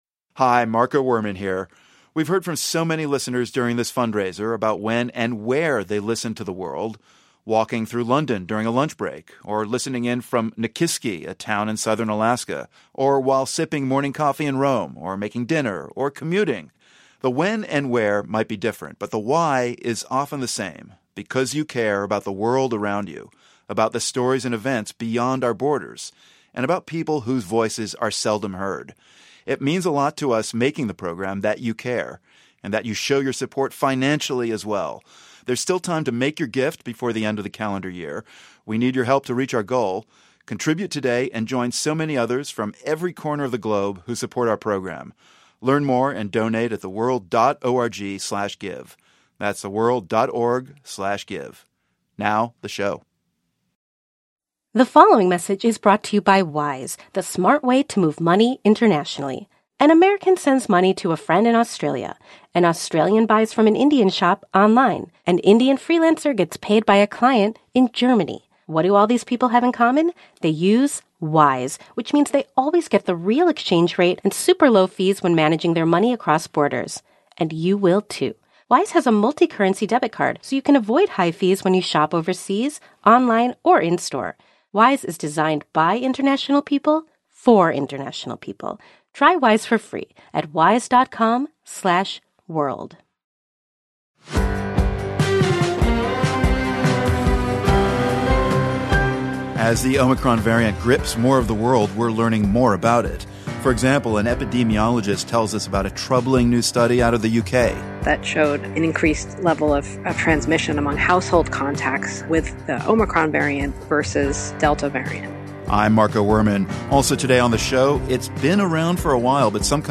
We hear from a leading epidemiologist about what we've learned since omicron was first announced.